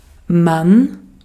Ääntäminen
IPA: [hæn]